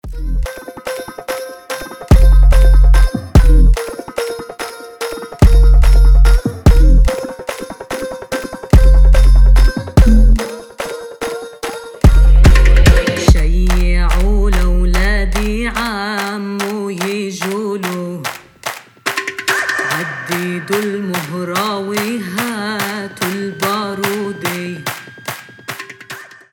• Качество: 320, Stereo
этнические
арабские
Tribal Trap
хлопки